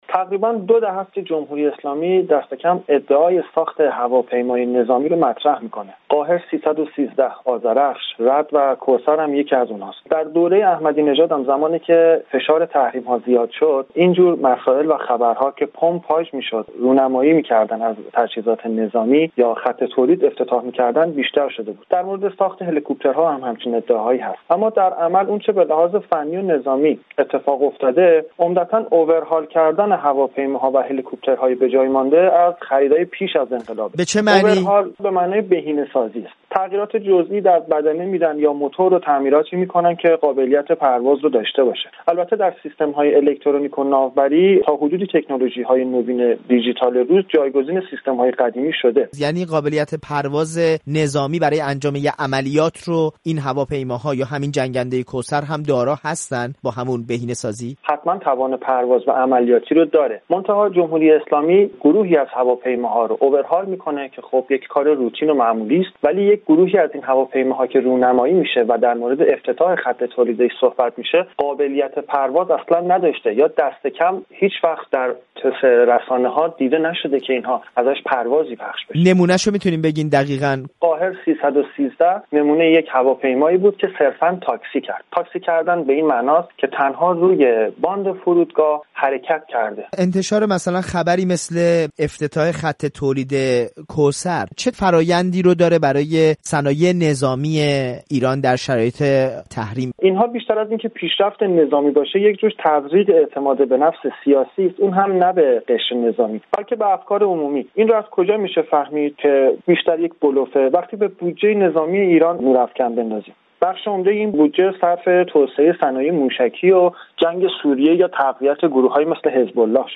گفت‌وگو